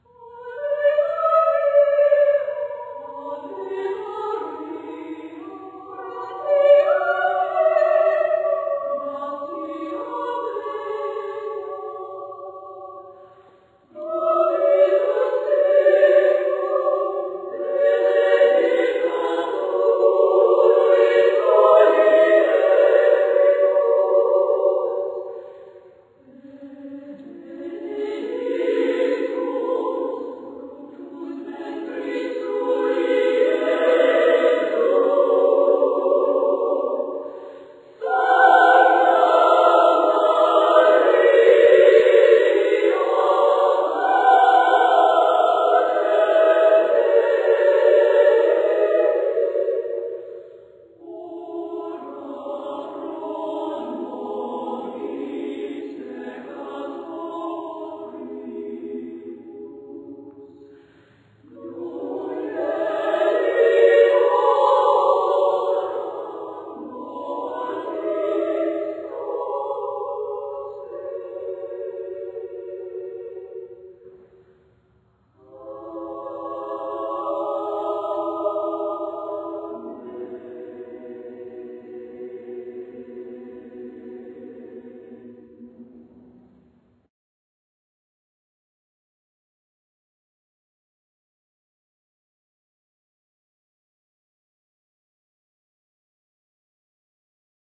Accompaniment:      A Cappella
Music Category:      Choral
beautiful, tender musical style